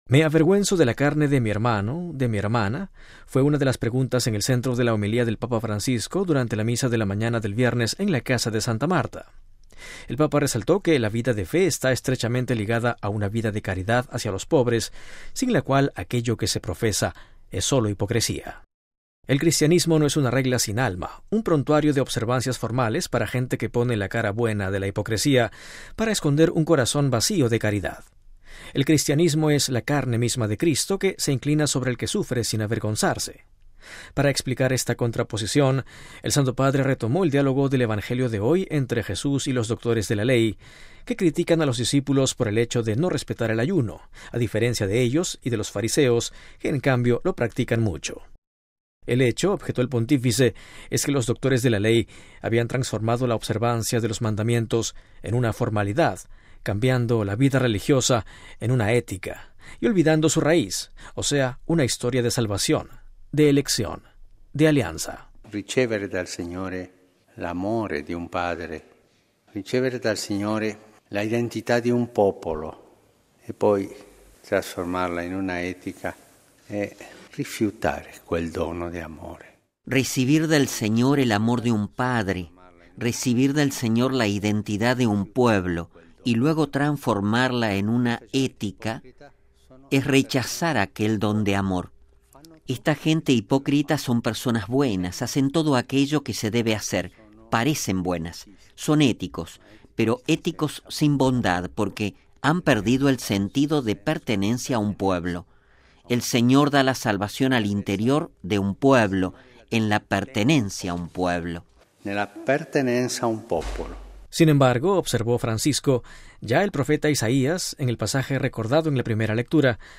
Fue una de las preguntas en el centro de la homilía del Papa Francisco, durante la Misa de la mañana del viernes en la Casa de Santa Marta. El Papa resaltó que la vida de fe está estrechamente ligada a una vida de caridad hacia los pobres, sin la cual aquello que se profesa es sólo hipocresía.